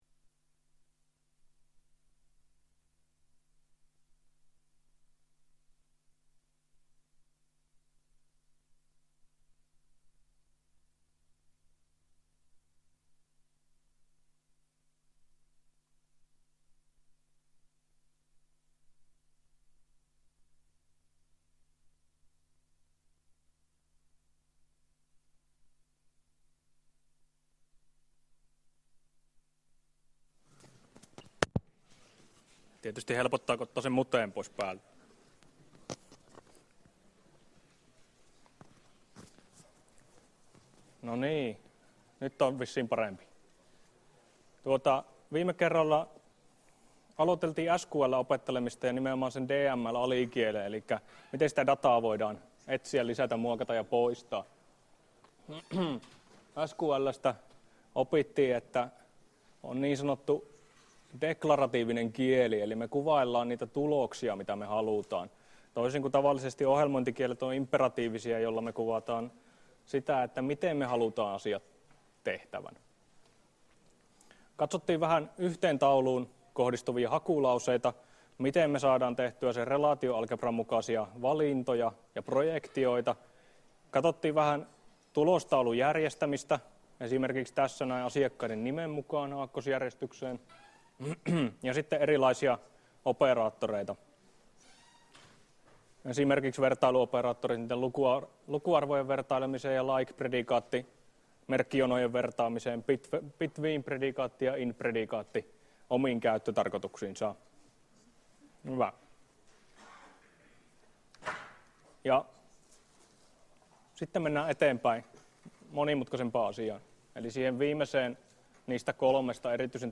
Luento 6 — Moniviestin